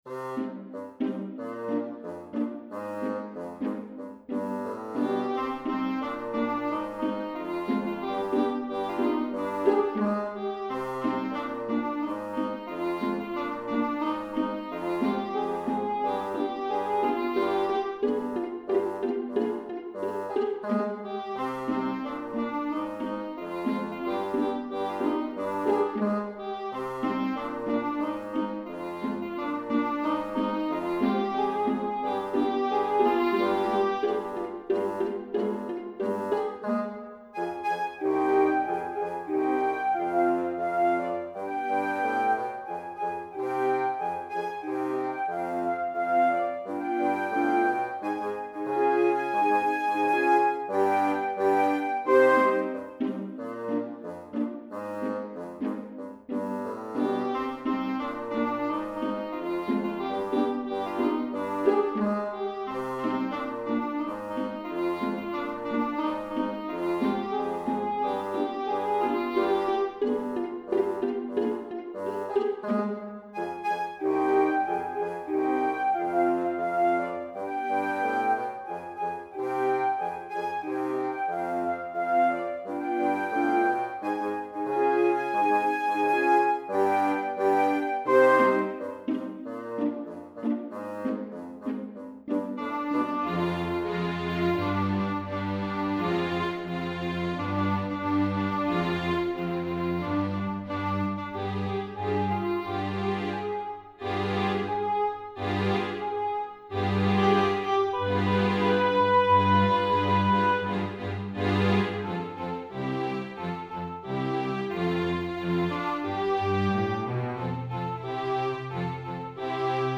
MP3  (no singing)